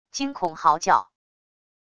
惊恐嚎叫wav音频